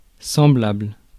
Ääntäminen
UK RP : IPA : /ˈneɪ.bə/